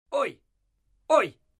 Звуки ой
Звук ой мужской